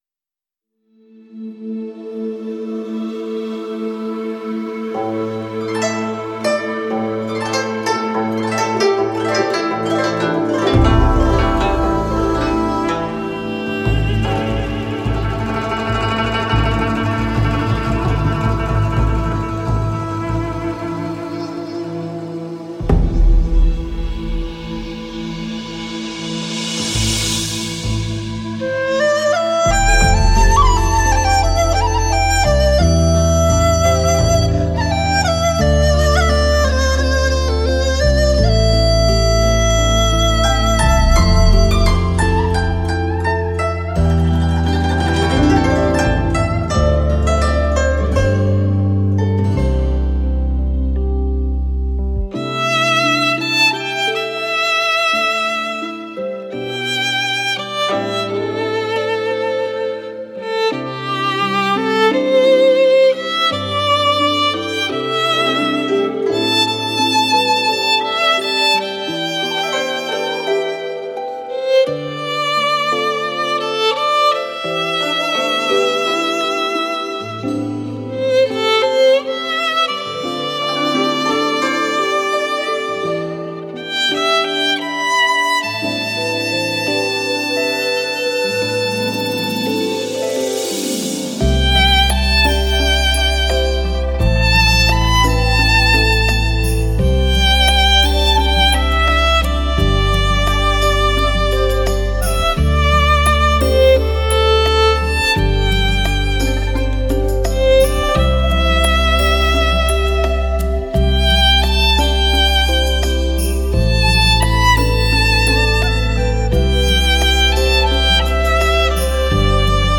小提琴演奏
柔情小提琴的万种风情 经典金曲 发烧共鸣
西乐与中乐遇见之美 传统与现代触碰之醉